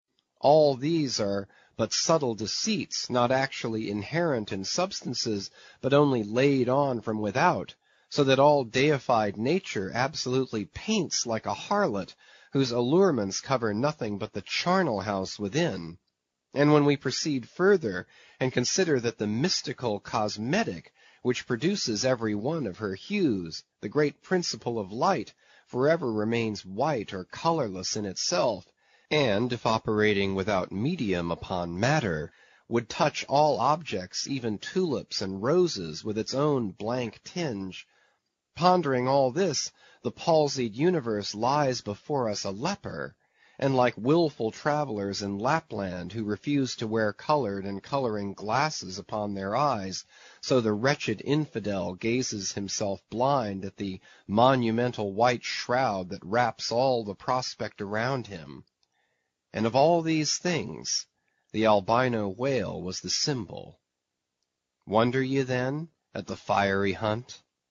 英语听书《白鲸记》第465期 听力文件下载—在线英语听力室